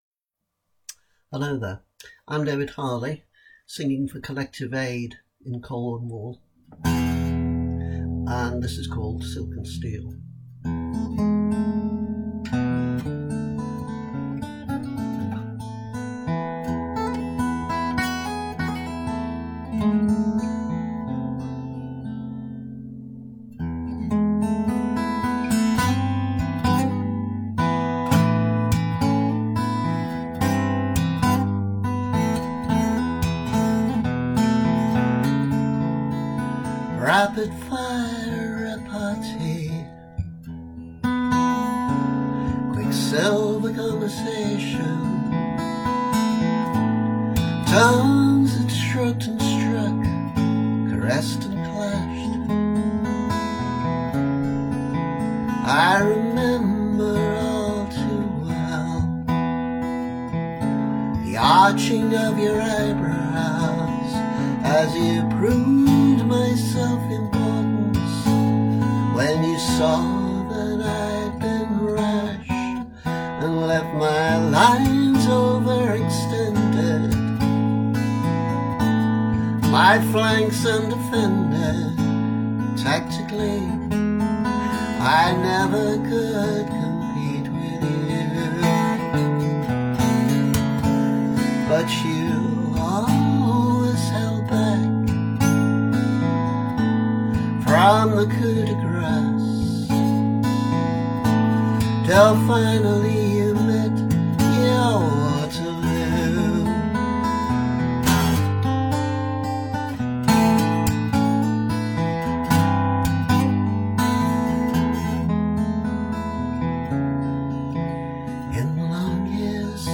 vocals; acoustic/electric/slide guitars.